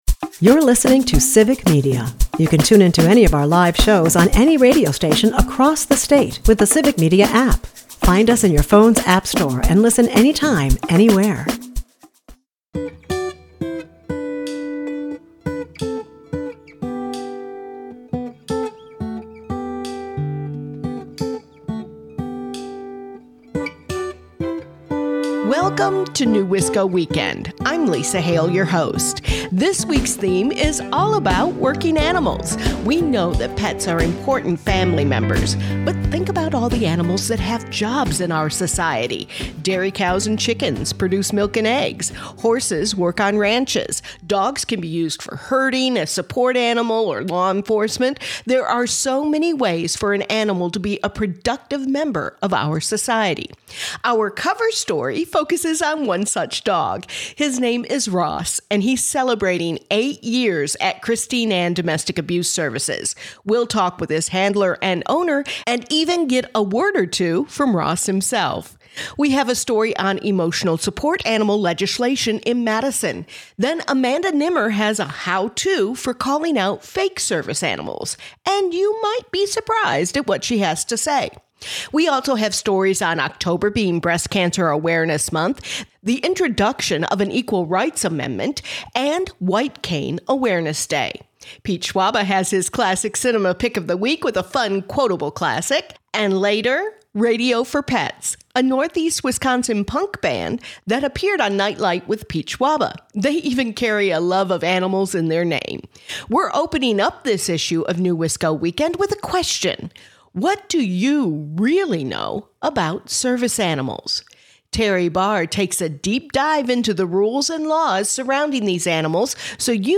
We have stories on Service animals and the law, legislation in Madison on emotional service animals. Radio for Pets is our musical guest.